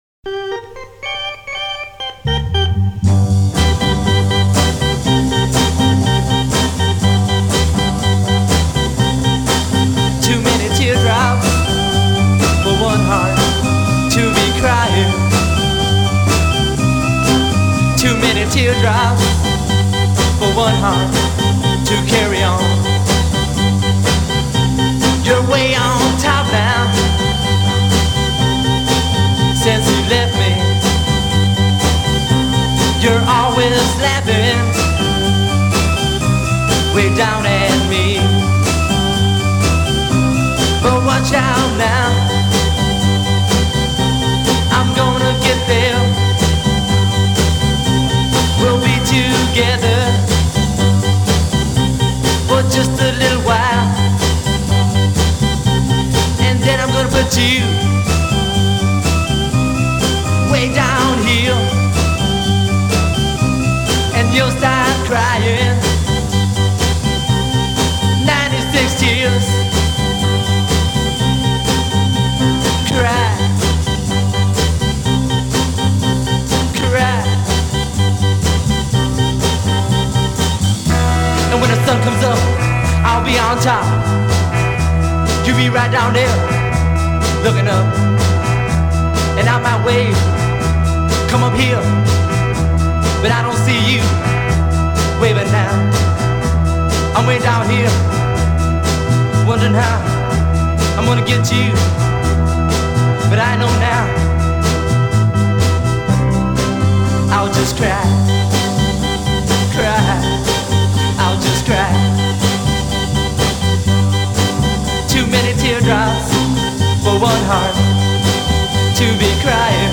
garage rock hit